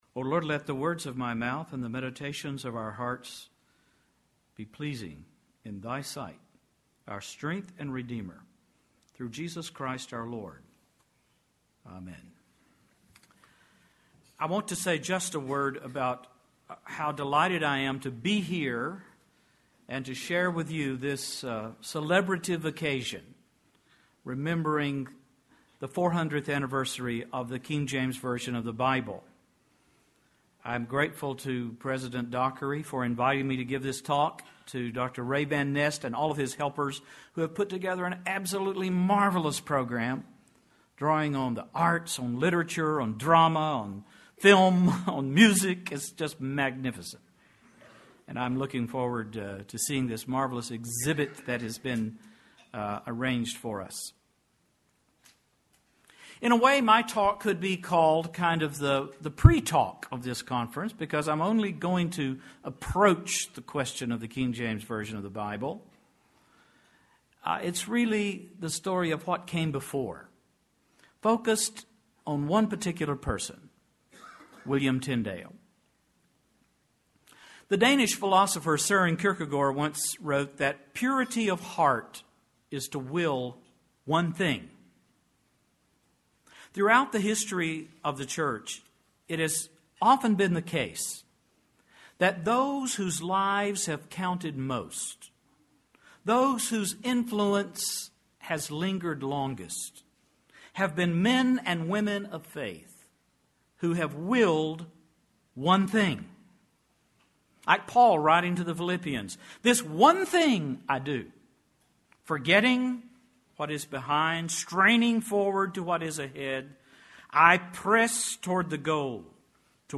KJV400 Festival
AL Address: William Tyndale and the Making of the English Bible Recording Date